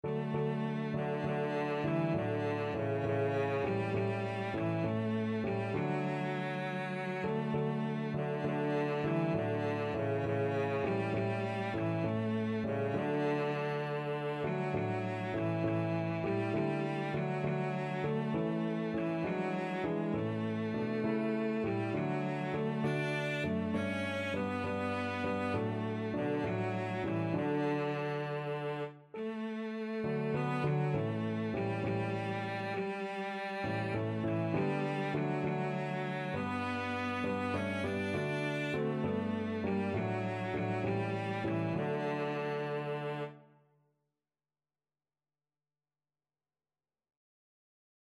Christian Christian Cello Sheet Music Faith is the Victory
Cello
Db4-D5
6/8 (View more 6/8 Music)
D major (Sounding Pitch) (View more D major Music for Cello )
Classical (View more Classical Cello Music)